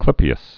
(klĭpē-əs)